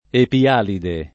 [ epi- # lide ]